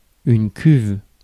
Ääntäminen
Ääntäminen France: IPA: [kyv] Haettu sana löytyi näillä lähdekielillä: ranska Käännös Ääninäyte Substantiivit 1. tank 2. vat US 3. tub Suku: f .